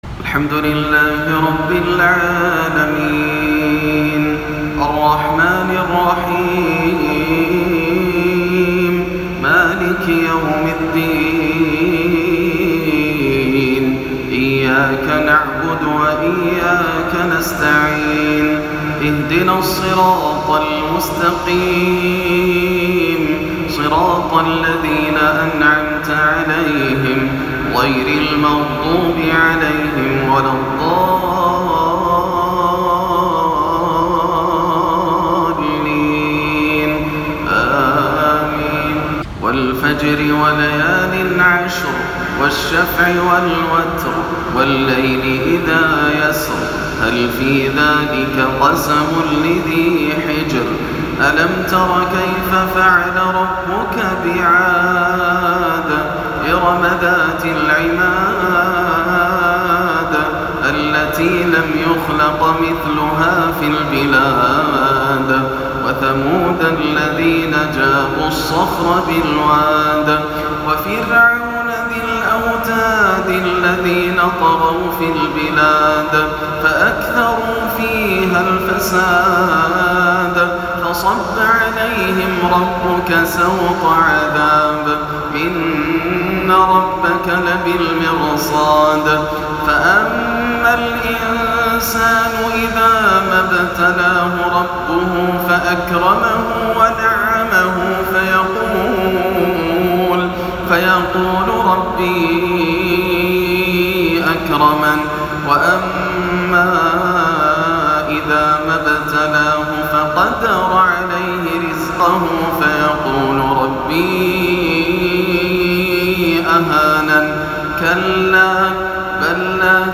تلاوة خاشعة لسورتي الفجر والليل من جامع عبدالله اللحيدان - مغرب الأحد 29-7 > عام 1439 > الفروض - تلاوات ياسر الدوسري